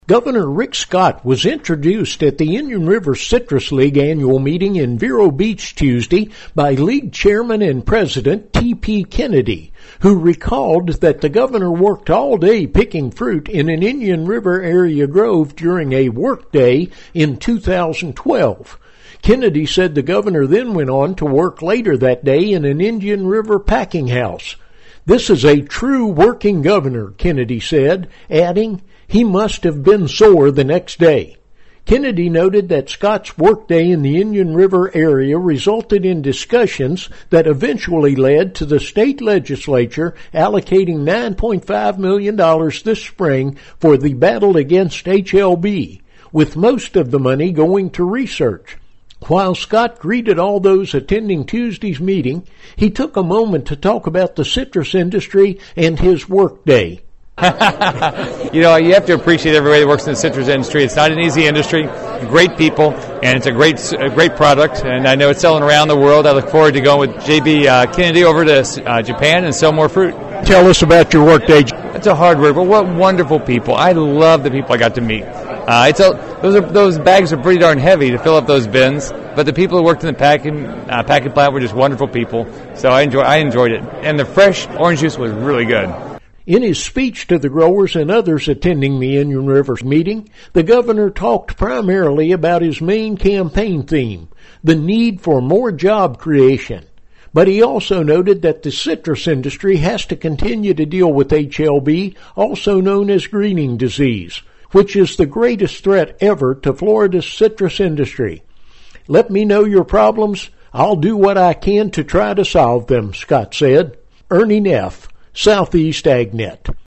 Gov. Rick Scott discussed the citrus industry and his “work day” in the Indian River citrus area in 2012 during the Indian River Citrus League’s annual meeting Tuesday.